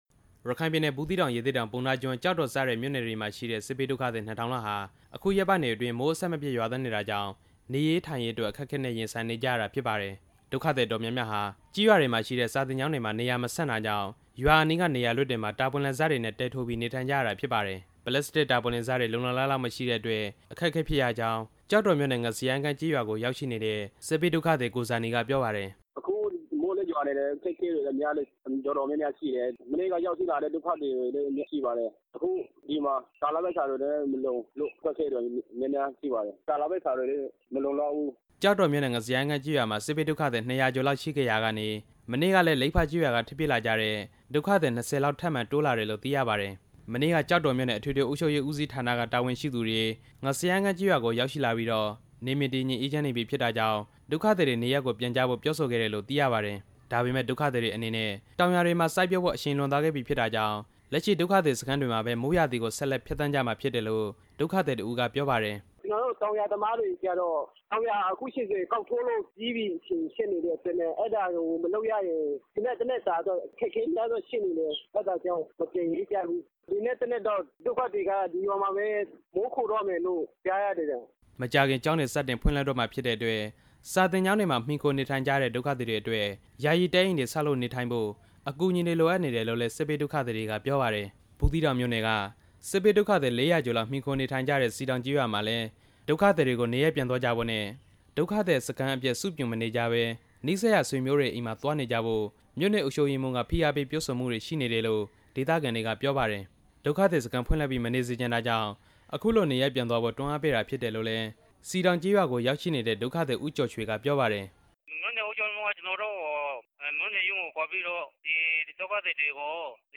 ရခိုင်စစ်ဘေးဒုက္ခသည်တွေရဲ့ အခြေအနေ တင်ပြချက်